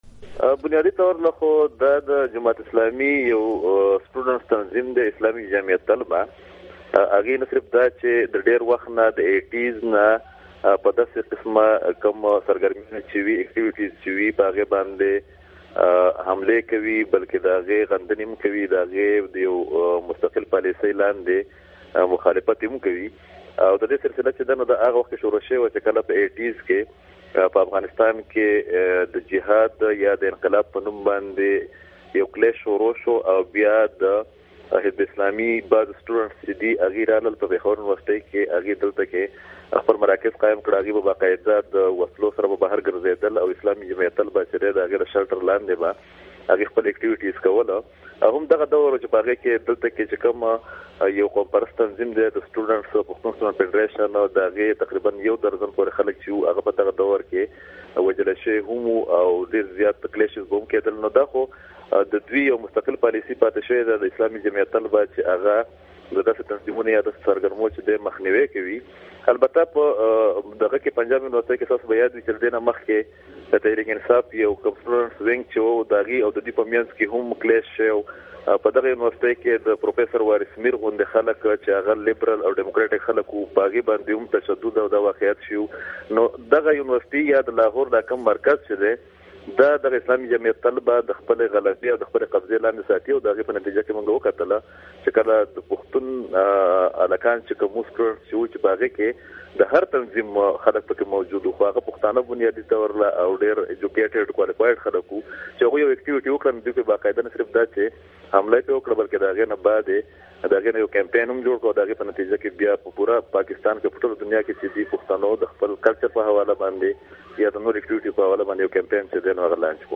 مرکې